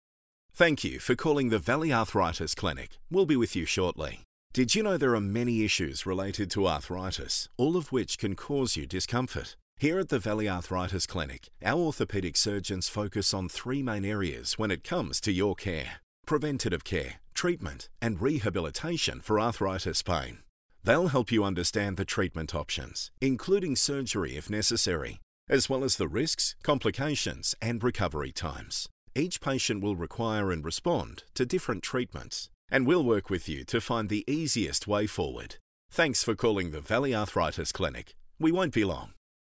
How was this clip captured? • Neumann TLM103 / Rode NT2a / Sennheiser MKH416